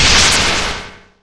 se_slash.wav